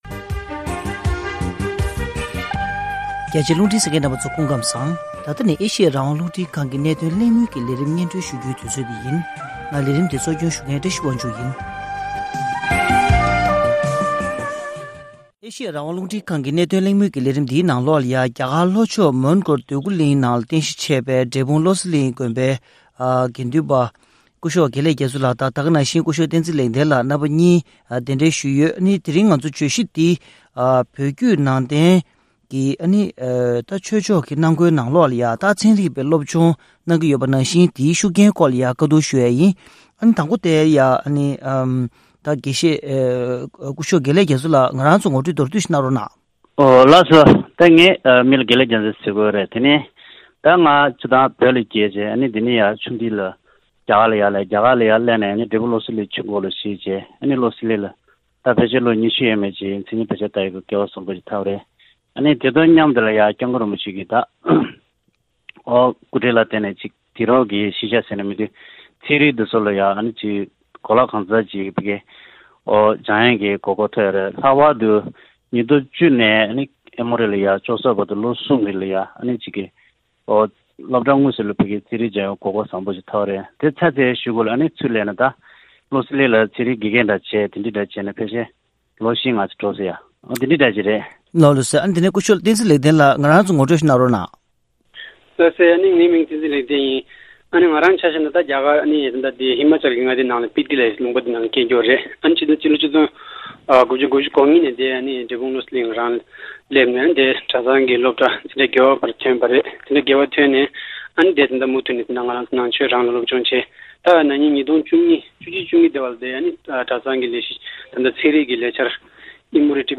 གནད་དོན་གླེང་མོལ